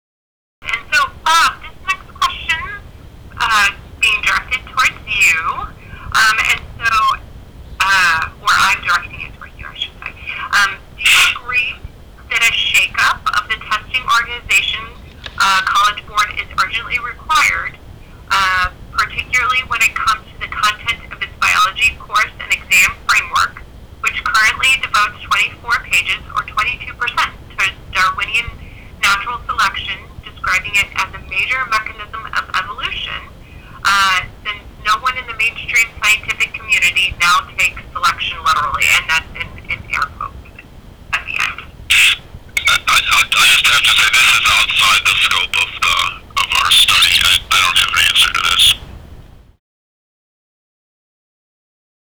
I listened-in to the webinar and submitted a written question for comment related to my recent College Board natural selection racket story.
AUDIO OF Q&A: